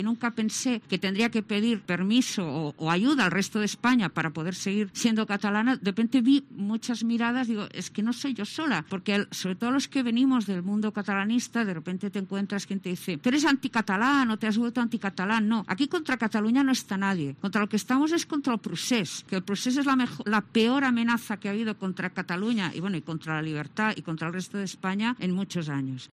La periodista Anna Grau explica que están en contra del separatismo